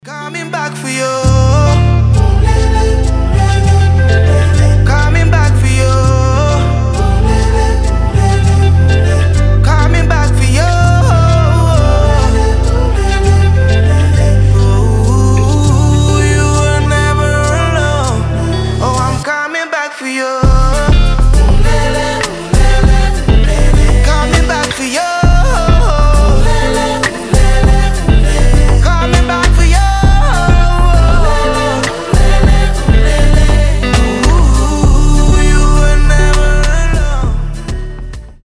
• Качество: 320, Stereo
африканские